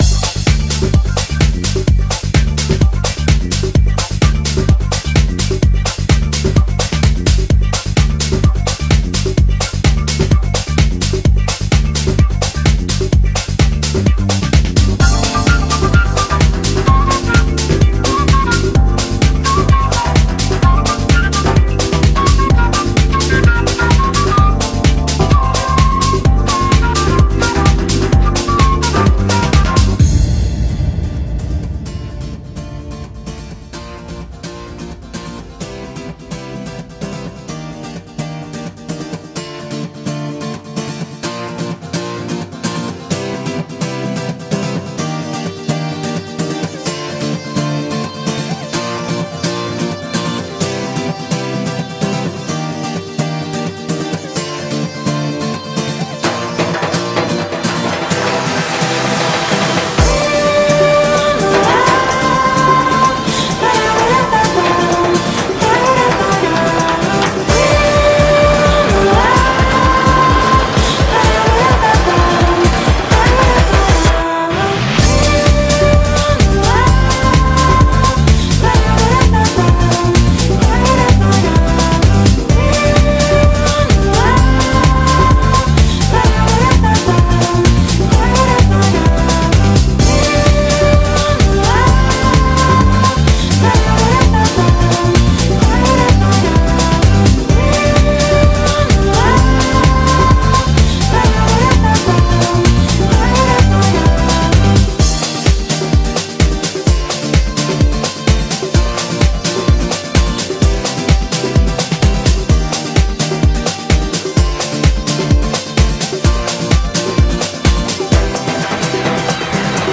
BPM128
Audio QualityPerfect (High Quality)
Comments[LATIN HOUSE]